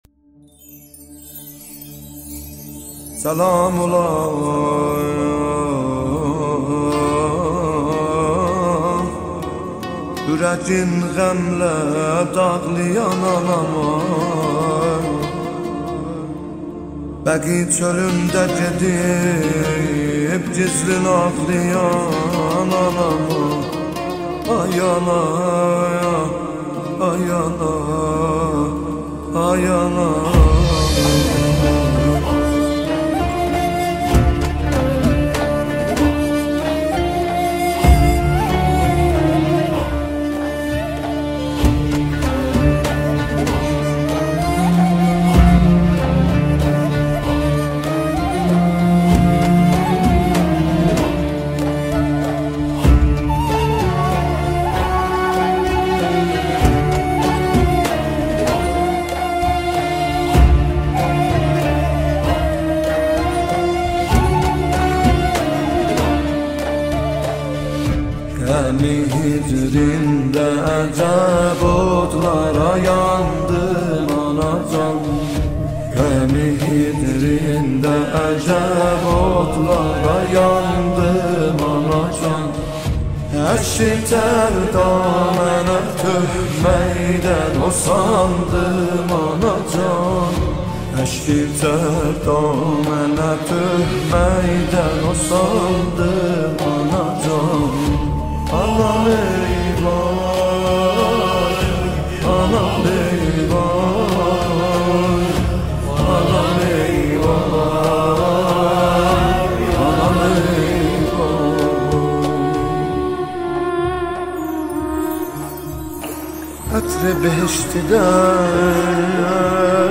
مداحی ترکی
ویژه ایام فاطمیه